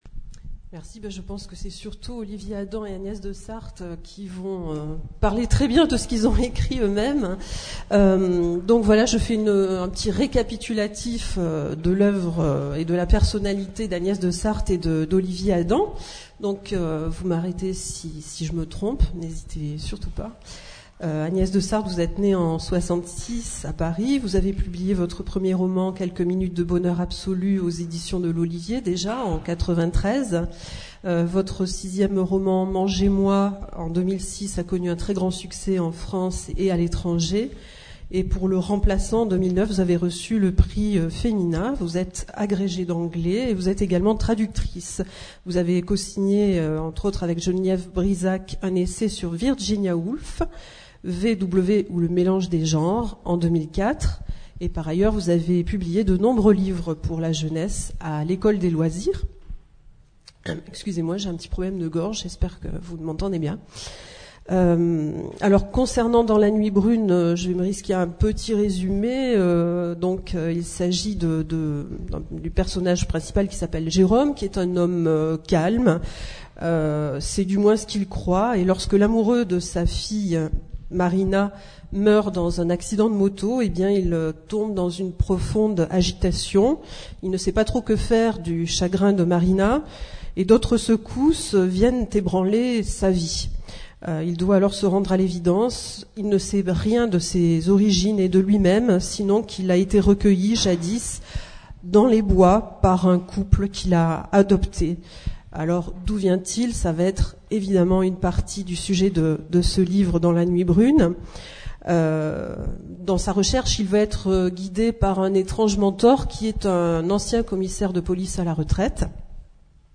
Rencontre avec Agnès Desarthe pour "Dans la nuit brune" (éd. de l'Olivier, 2010) et Olivier Adam pour "Le coeur régulier" (éd. de l'Olivier, 2010).
Rencontre littéraire